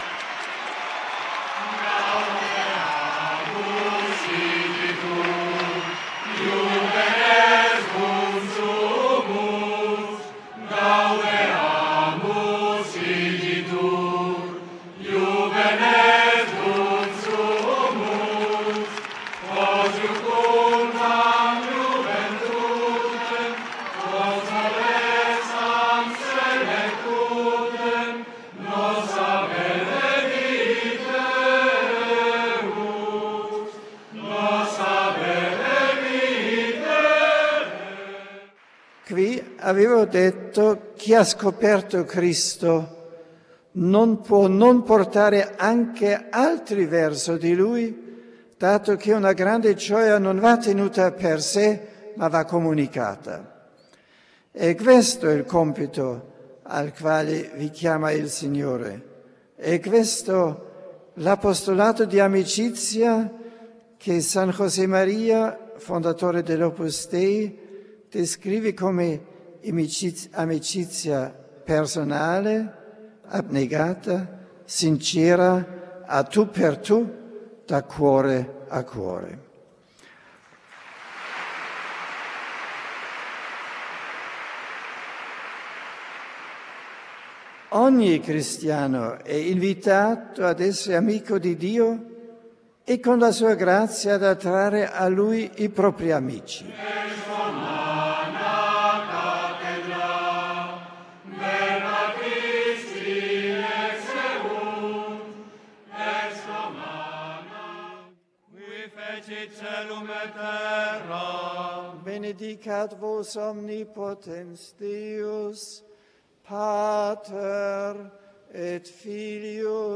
Discurso de Benedicto XVI a los participantes en el congreso internacional UNIV
Ciudad del Vaticano, 10 abril 2006.